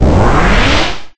Fog2.ogg